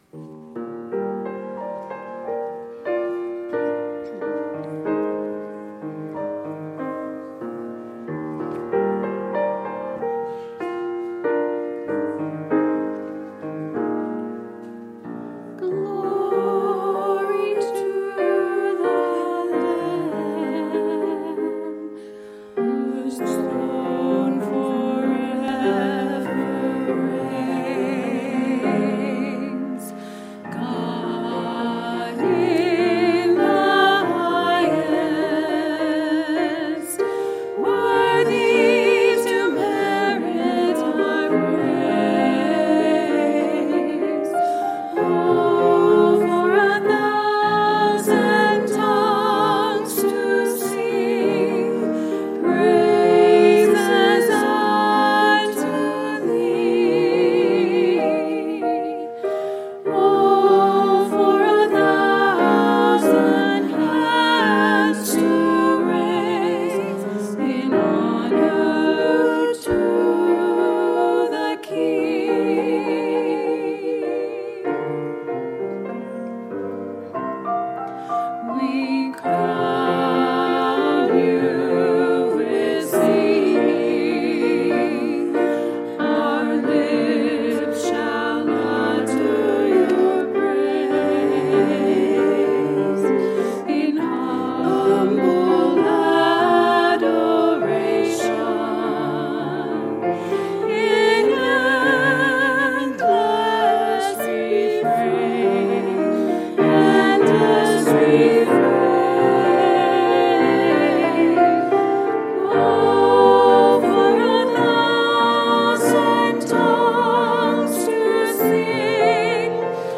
John 17 Ladies Trio “O For A Thousand Tongues” words and by David Binion.